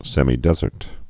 (sĕmē-dĕzərt, sĕmī-)